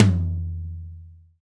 TOM TOM 88.wav